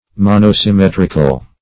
Search Result for " monosymmetrical" : The Collaborative International Dictionary of English v.0.48: Monosymmetric \Mon`o*sym*met"ric\, Monosymmetrical \Mon`o*sym*met"ric*al\, a. [Mono- + symmetric, -ical.]
monosymmetrical.mp3